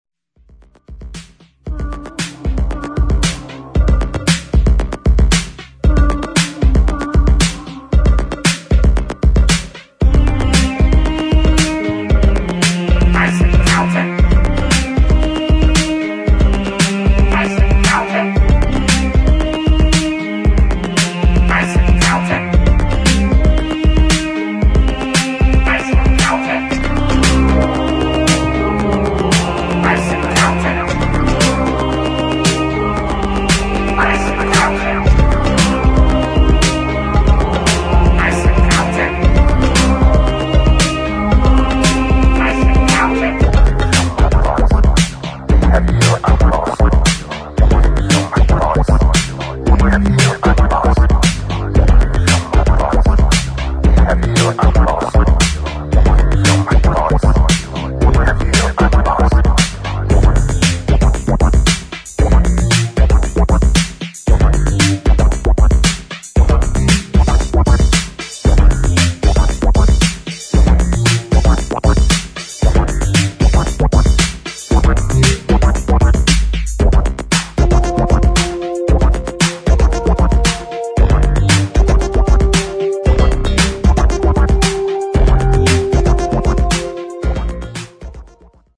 [ DEEP HOUSE / DISCO ]